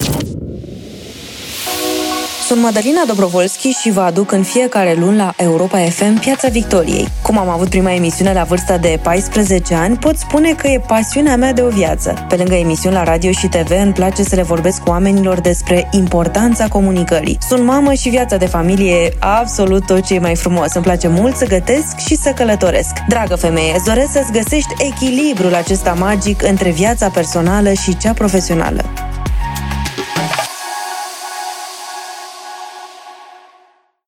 Câteva dintre mesajele colegelor noastre: